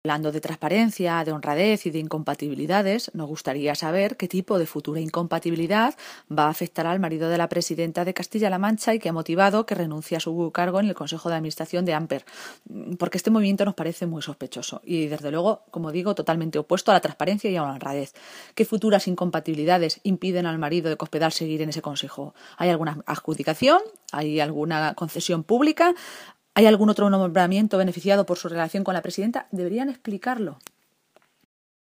Maestre se pronunciaba de esta manera esta mañana, en una comparecencia ante los medios de comunicación en la capital de Castilla-La Mancha, donde hoy coincidía con la reunión del comité nacional de dirección del PP.
Cortes de audio de la rueda de prensa